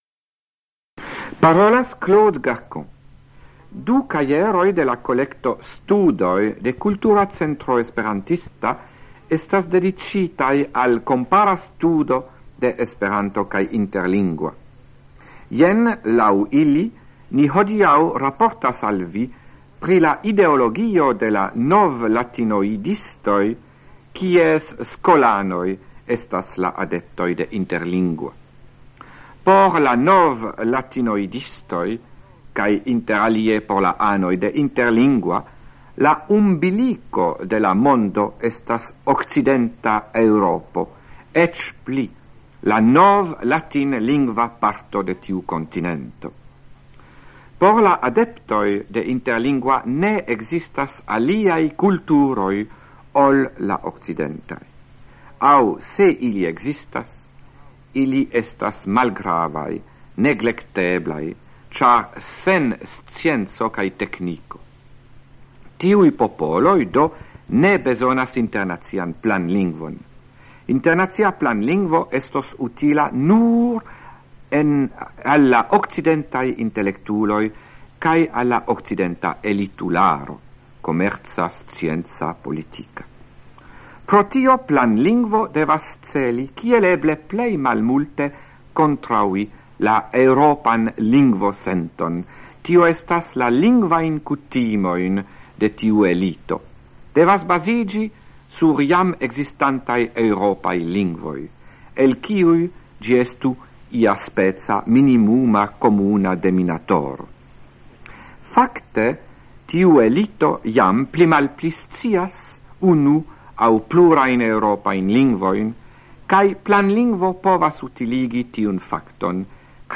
Radioprelegoj en la jaro 1977